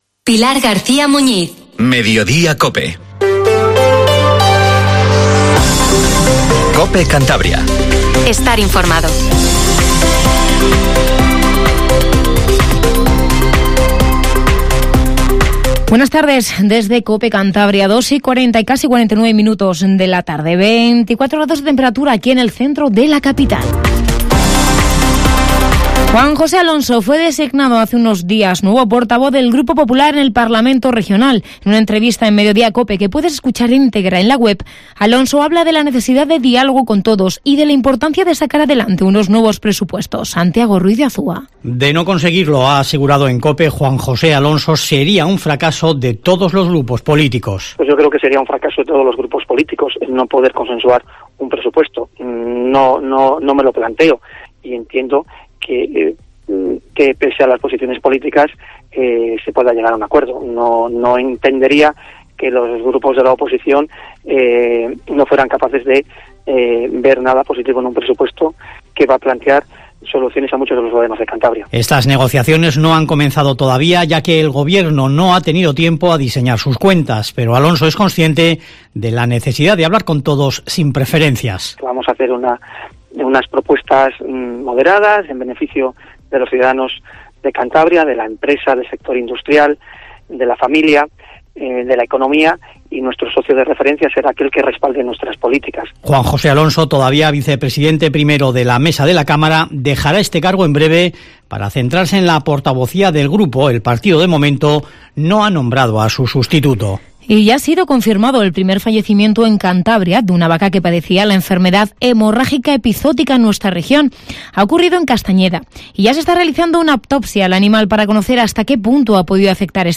Informativo MEDIODIA en COPE CANTABRIA 14:48